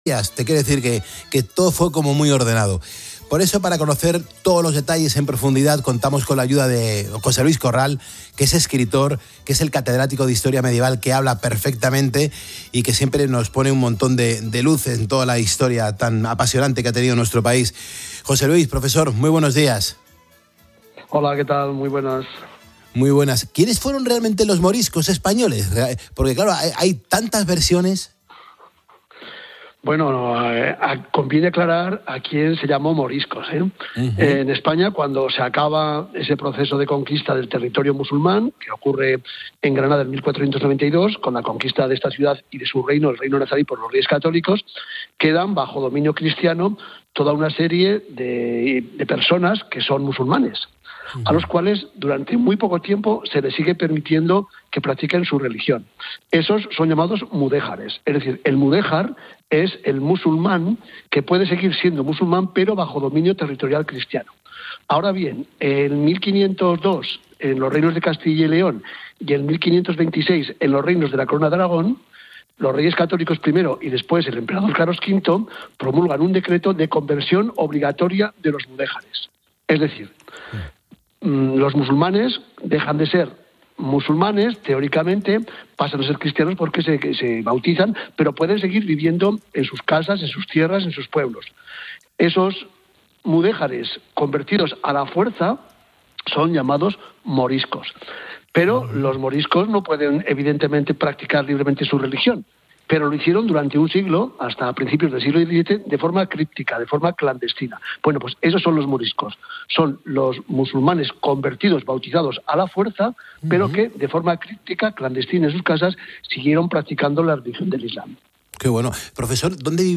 El catedrático de Historia Medieval analiza en 'Poniendo las Calles' las graves consecuencias de la marcha de 300.000 artesanos y agricultores...